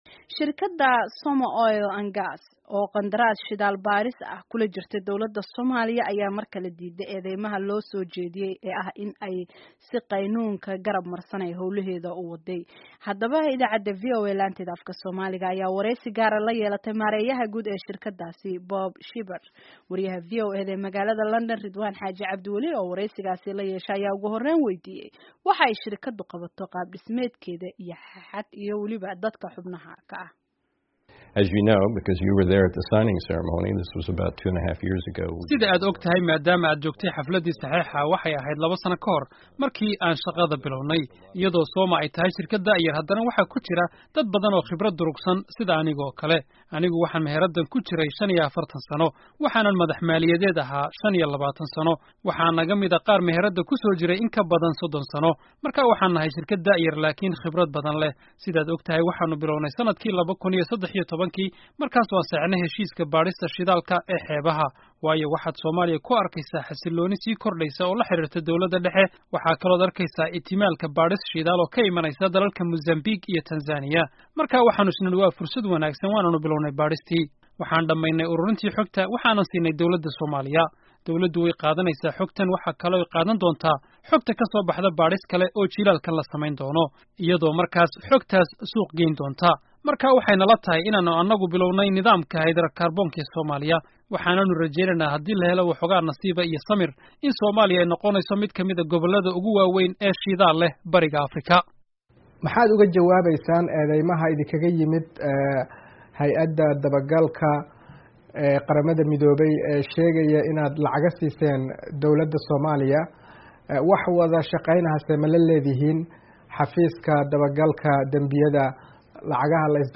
Dhageyso Wareysiga Shirkadda Soma Oil and Gas